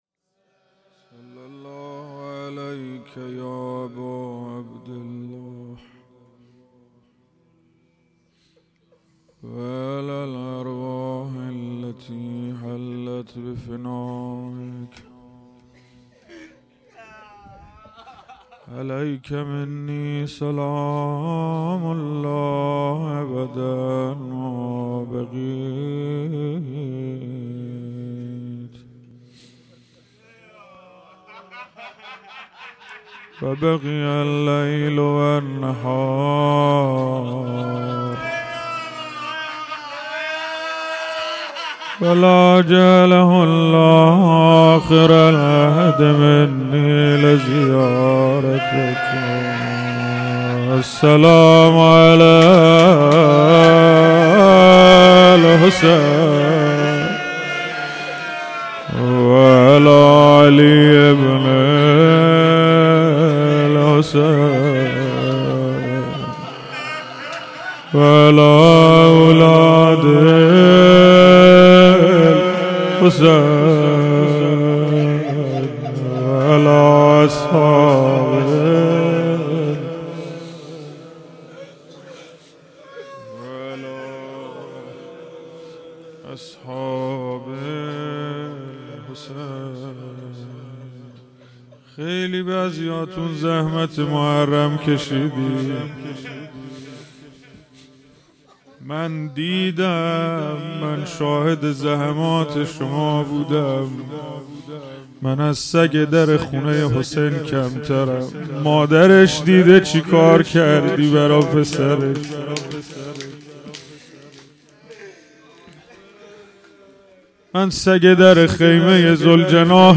هیئت حسن جان(ع) اهواز - روضه|روز اول دهه اول محرم